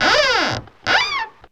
Index of /90_sSampleCDs/E-MU Producer Series Vol. 3 – Hollywood Sound Effects/Human & Animal/WoodscrewSqueaks
WOOD SQUEA02.wav